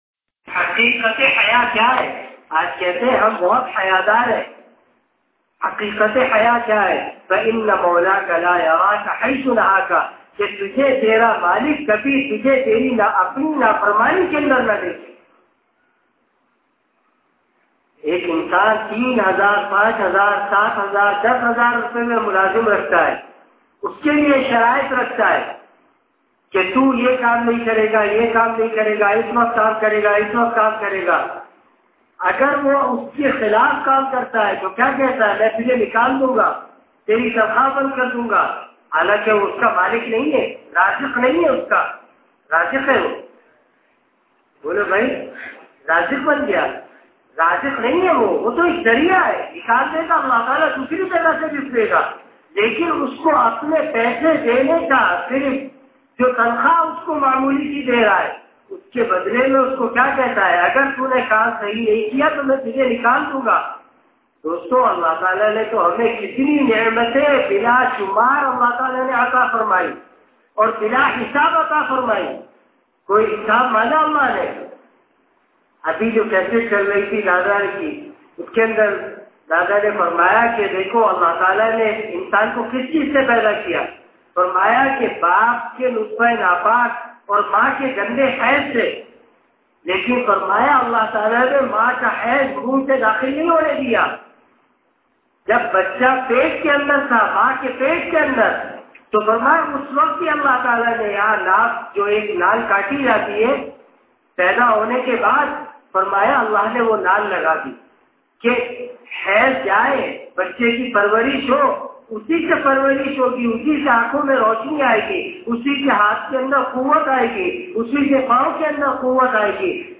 Bayan : 2012-09-07 |